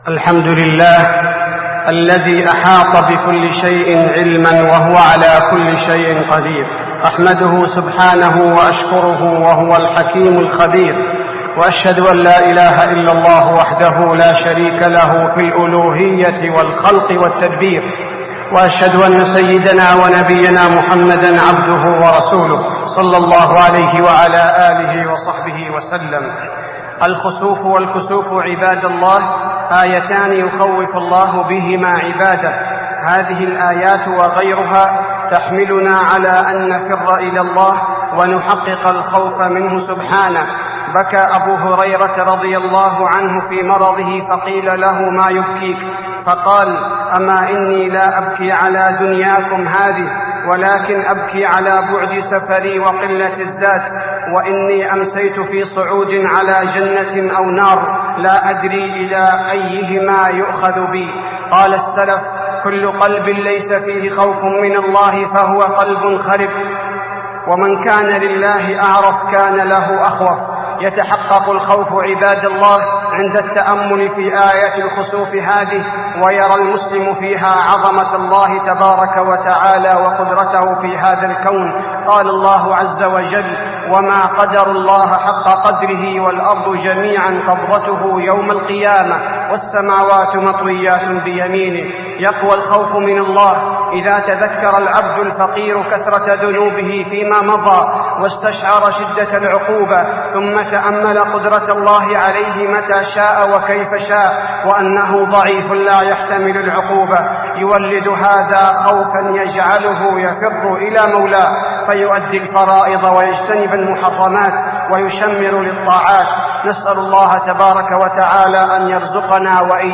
خطبة الخسوف المدينة - الشيخ عبدالباري الثبيتي
تاريخ النشر ١٤ رمضان ١٤٢٤ هـ المكان: المسجد النبوي الشيخ: فضيلة الشيخ عبدالباري الثبيتي فضيلة الشيخ عبدالباري الثبيتي خطبة الخسوف المدينة - الشيخ عبدالباري الثبيتي